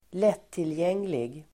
Uttal: [²l'et:tiljeng:lig]